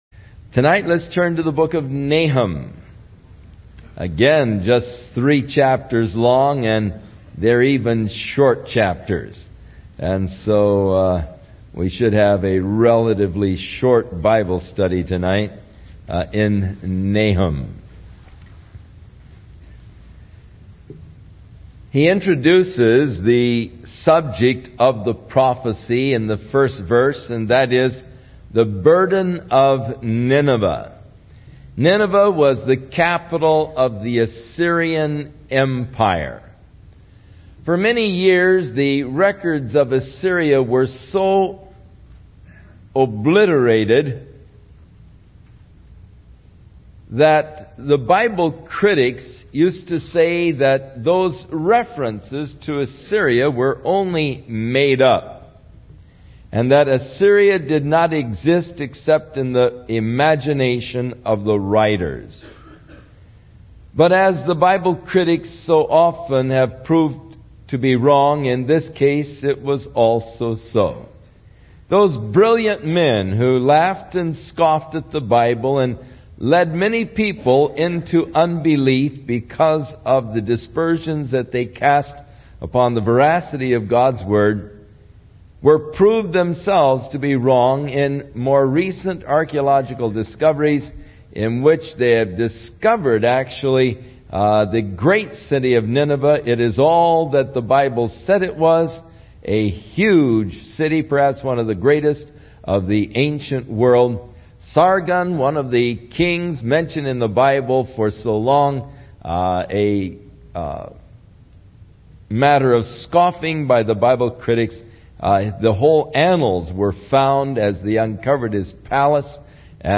Home / 01 Verse by Verse Teaching / Chuck Smith / Book 34 Nahum